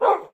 bark1.mp3